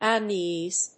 • / `ʌníːz(米国英語)
• / ʌˈni:z(英国英語)